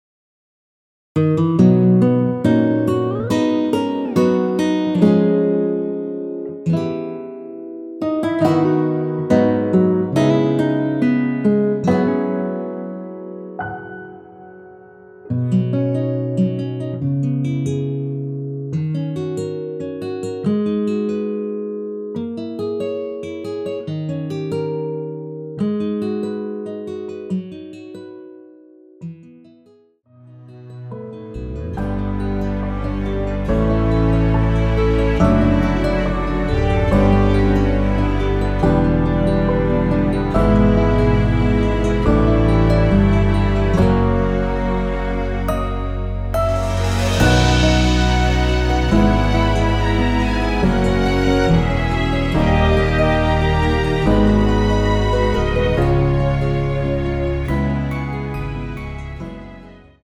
원키에서(-6)내린 MR입니다.
앞부분30초, 뒷부분30초씩 편집해서 올려 드리고 있습니다.
중간에 음이 끈어지고 다시 나오는 이유는